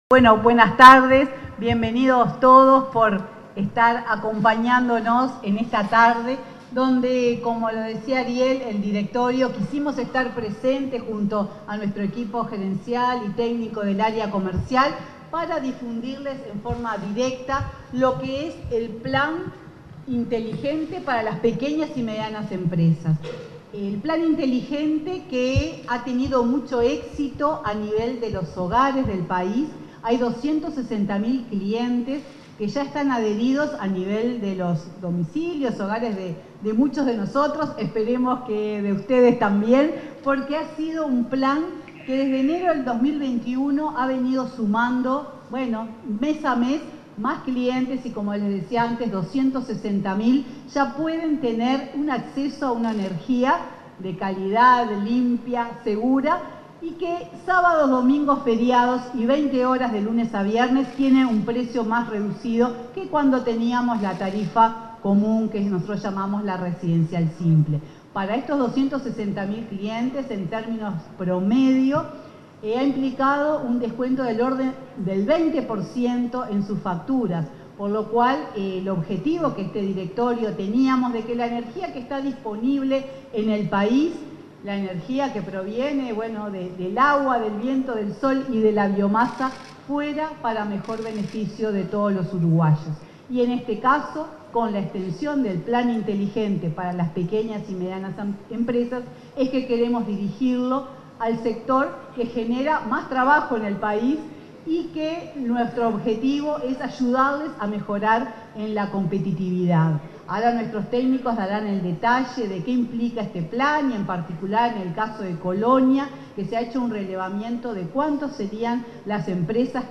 Palabras de la presidenta de UTE, Silvia Emaldi, en Colonia
En la ceremonia de presentación del Plan Inteligente para Pequeñas y Medianas Empresas, en Colonia, este 9 de octubre, se expresó la presidenta de UTE